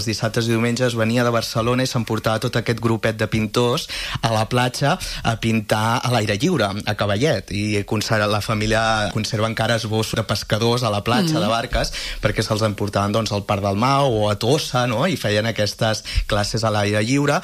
en una entrevista a l’FM i +.